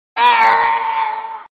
Lego Yoda Death Meme sound effects free download